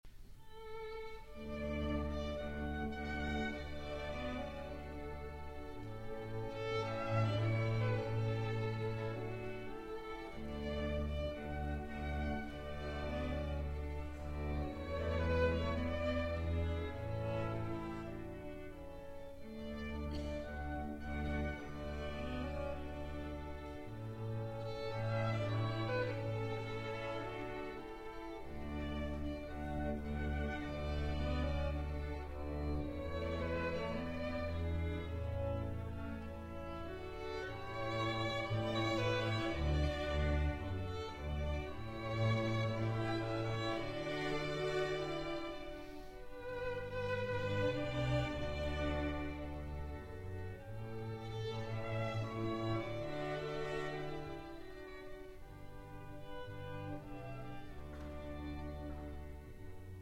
performed live
Piano